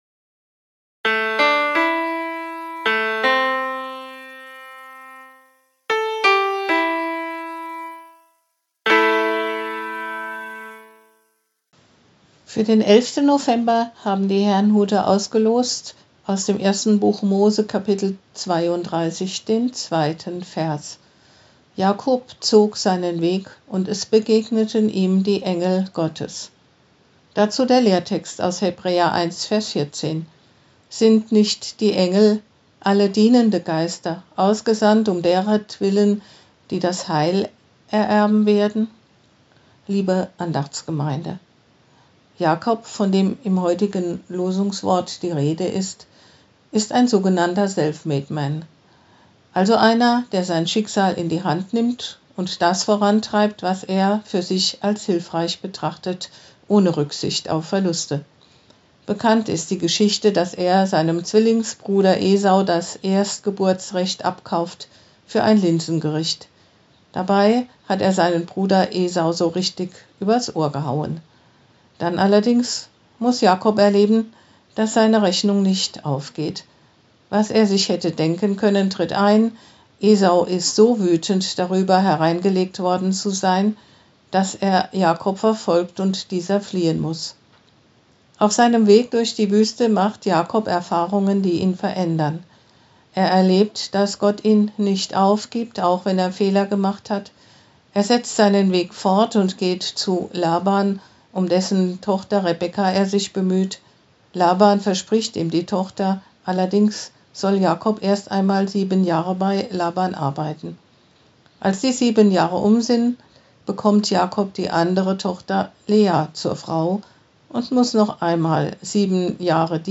Losungsandacht für Samstag, 11.11.2023
Losungsandachten